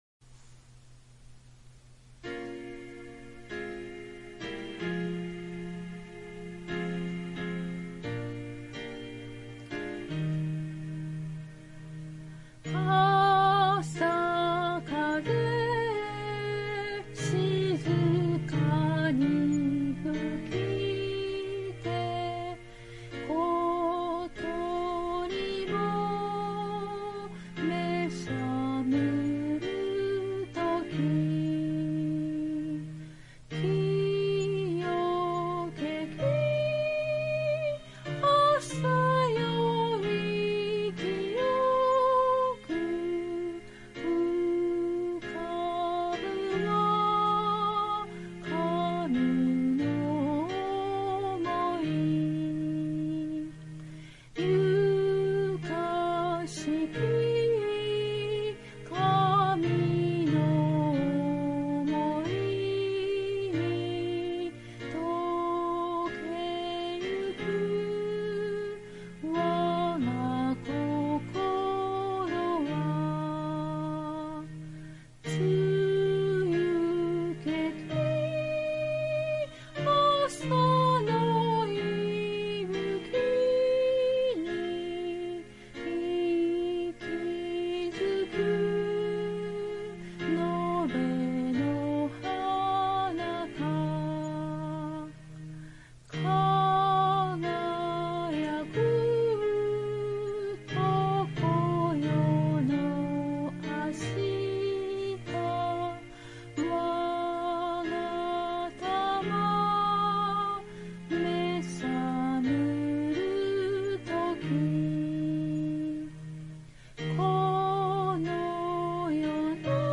讃美歌